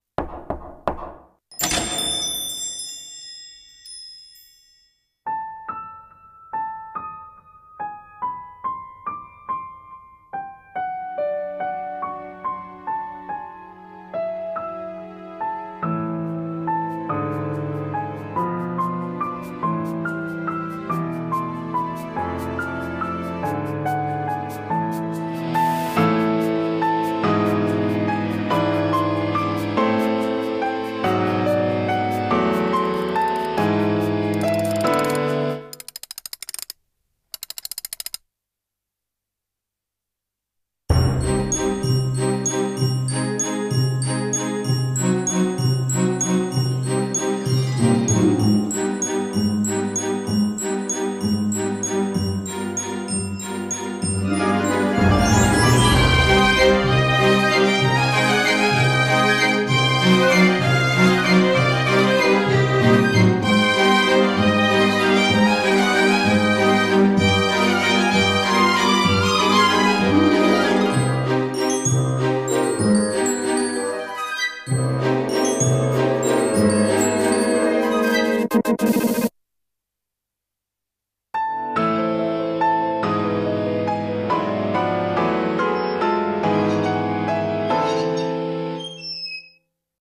CM風声劇「トワール商會骨董店」